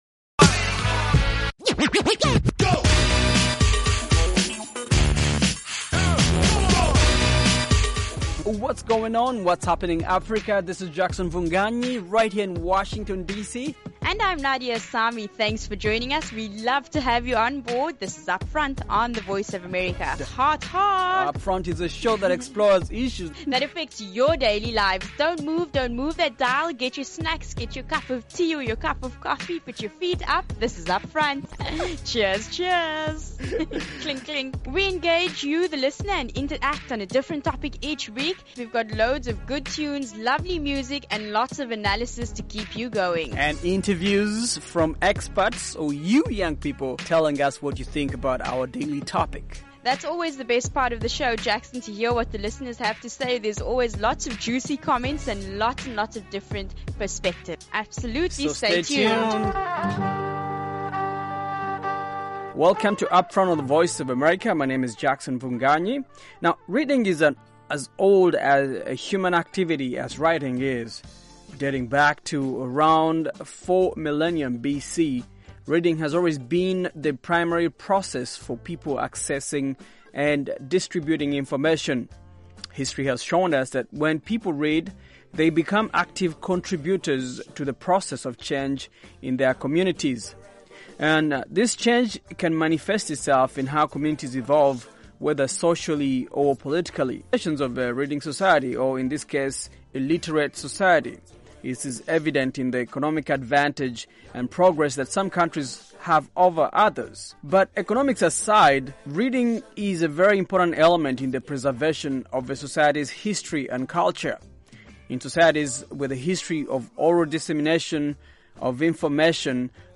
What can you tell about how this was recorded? On this fresh, fast-paced show